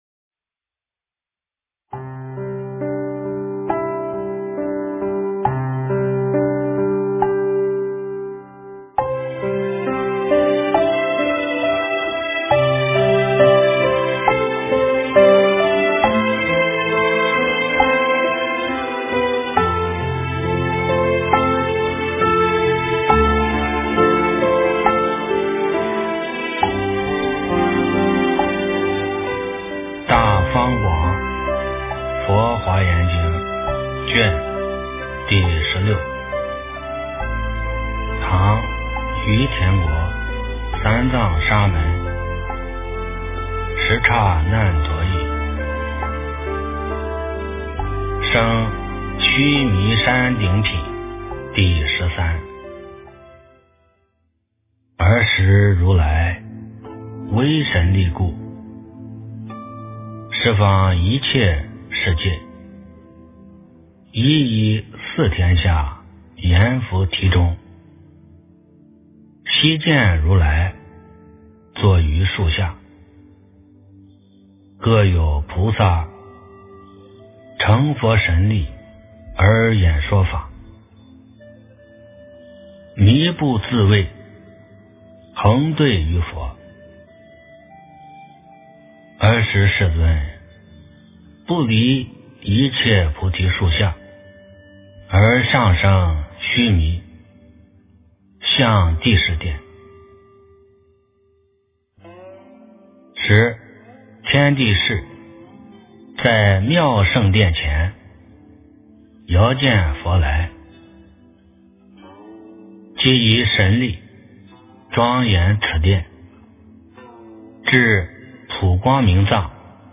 《华严经》16卷 - 诵经 - 云佛论坛